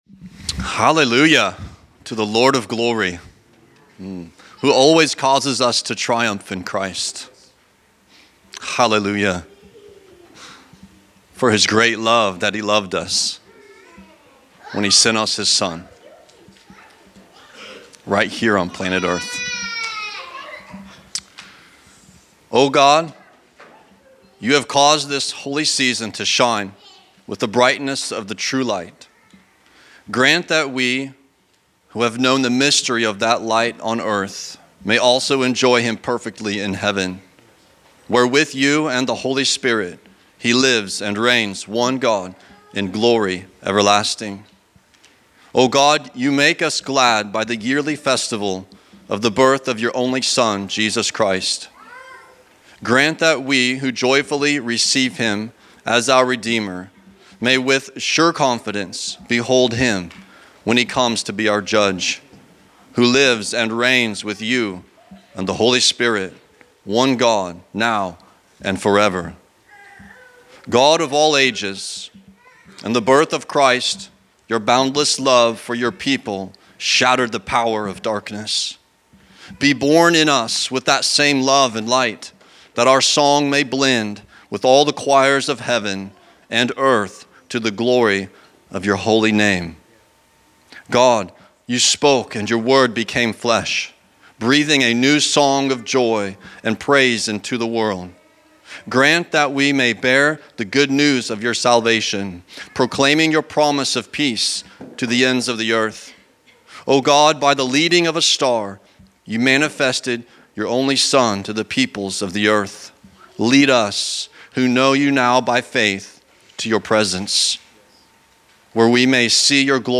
Advent Reading
Liturgical Reading